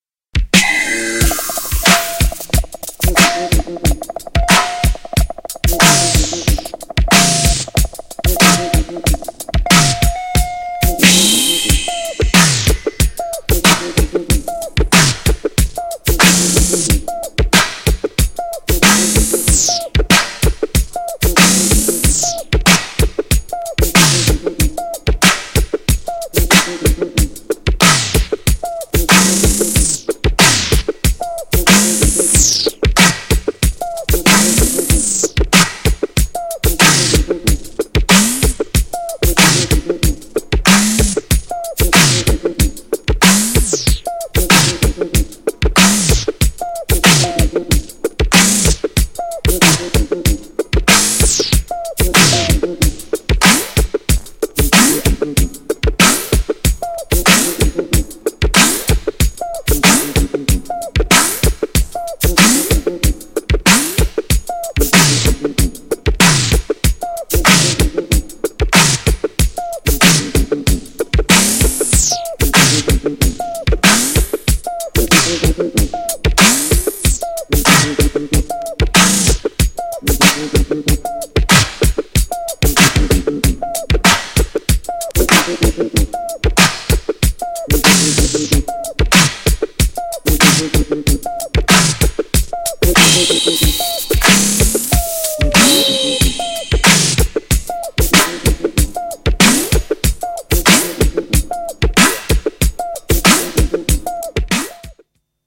GENRE Dance Classic
BPM 91〜95BPM